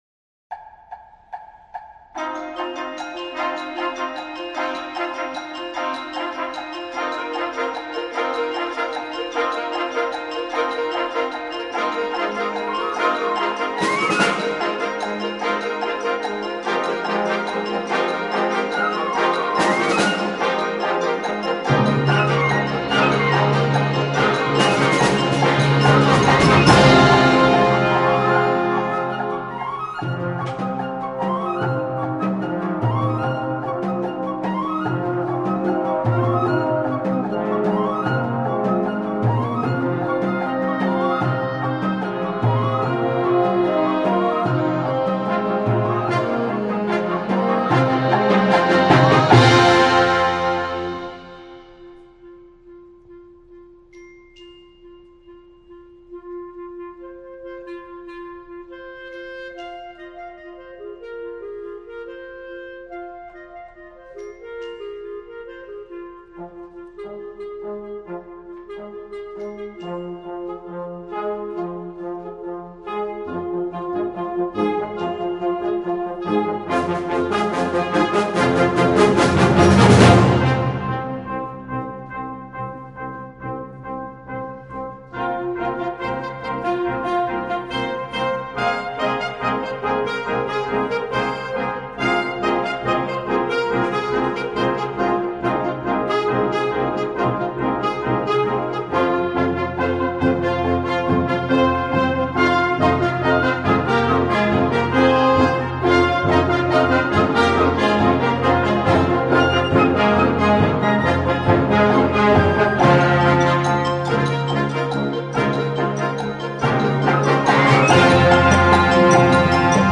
an introduction to minimalist music for younger ensembles
Concert Band